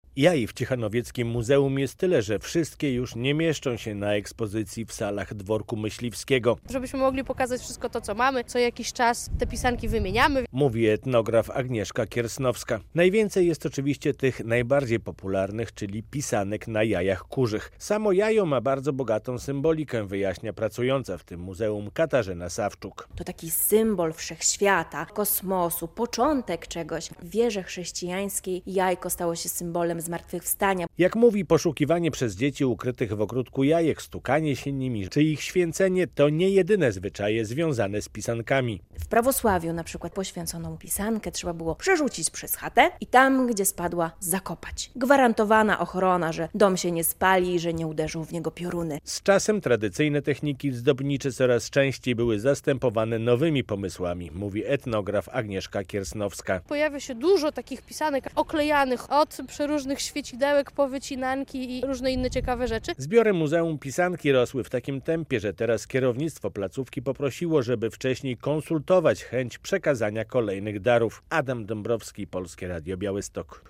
Muzeum Pisanki w ciechanowieckim skansenie - relacja